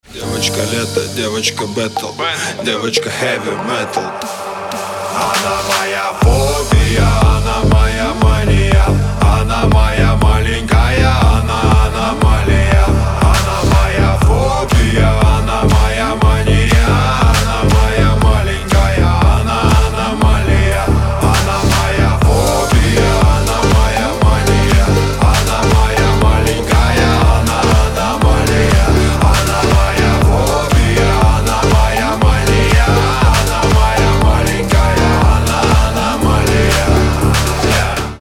• Качество: 320, Stereo
dance
club
клубнячок